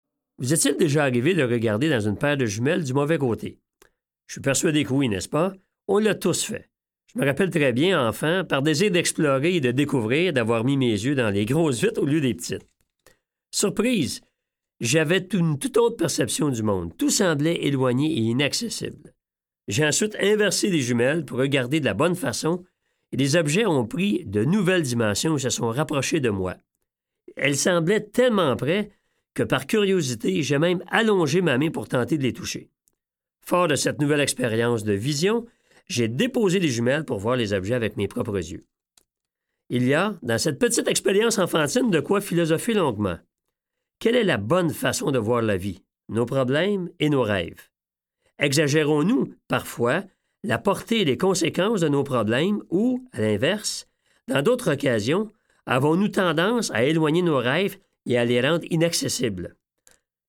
Ça pourrait être pire ! Coffragants Date de publication : 2018 Le livre audio Ça pourrait être pire ! est l’antidote idéal à la déprime.